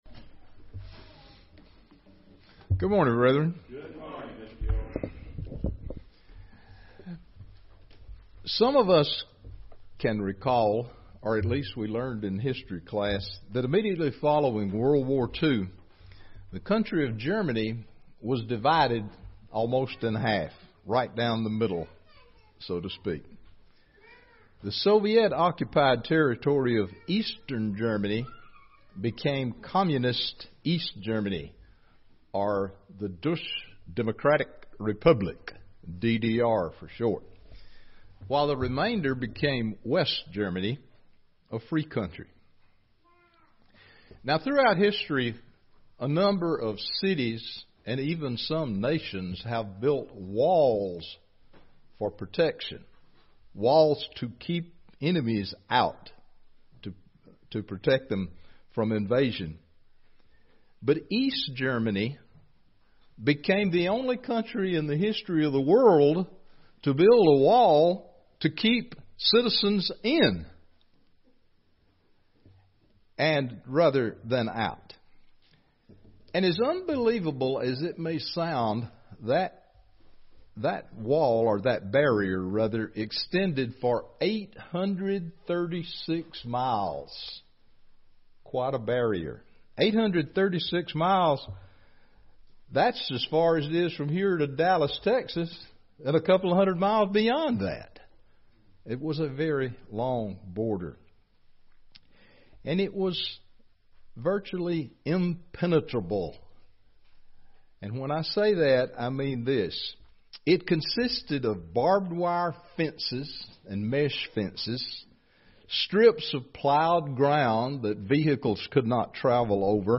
Given in Gadsden, AL
" UCG Sermon Studying the bible?